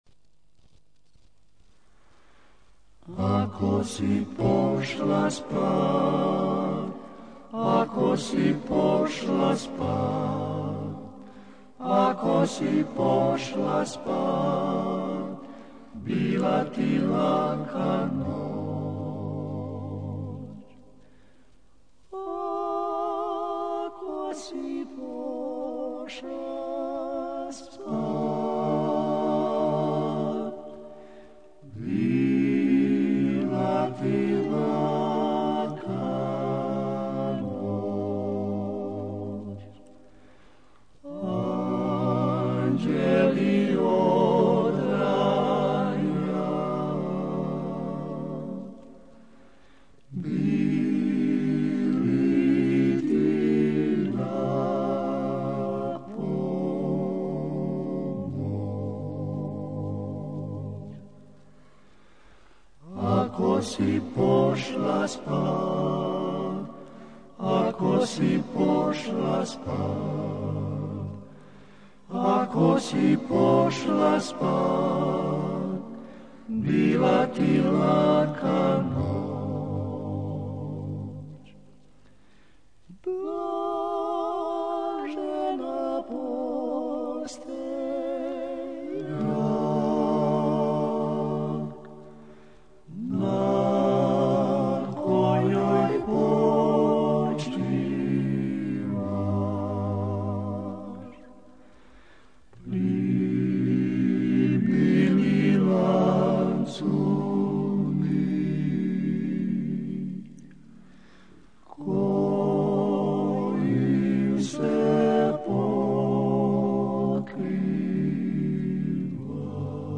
Dalmatian klapa song originally from Split.